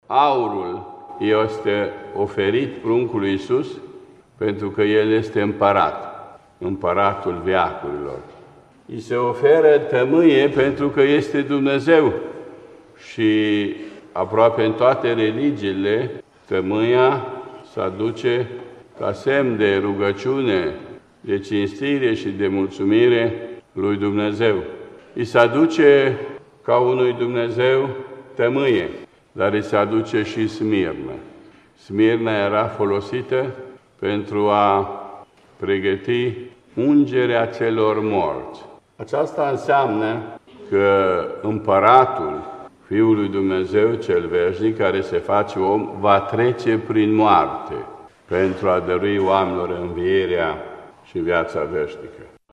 La Catedrala Patriarhală din Bucureşti ea a fost oficiată de Părintele Patriarh Daniel împreună cu un sobor de ierarhi, preoţi şi diaconi. În cuvântul său, el a spus că Sărbătoarea Naşterii Domnului este şi sărbătoarea darurilor, aşa cum fiul Domnului a primit daruri de la cei trei magi de la Răsărit:
26-dec.-Patriarhul-Daniel.mp3